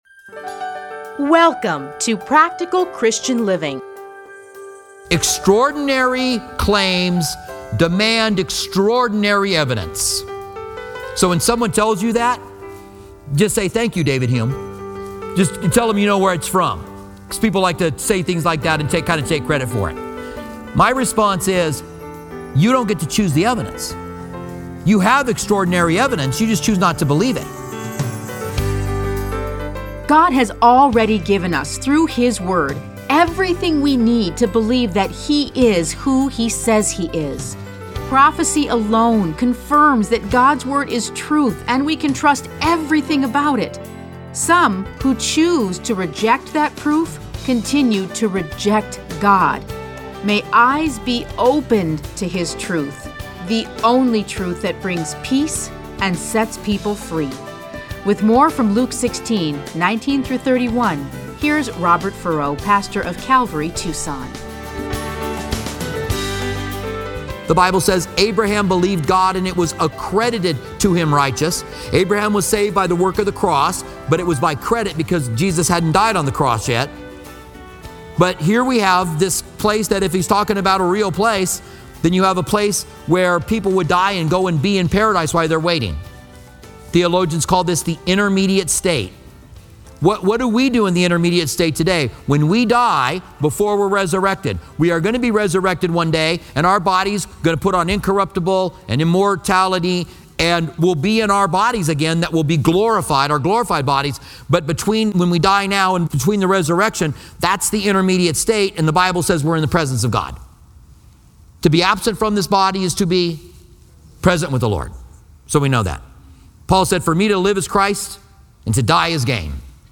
Listen to a teaching from Luke 16:19-31.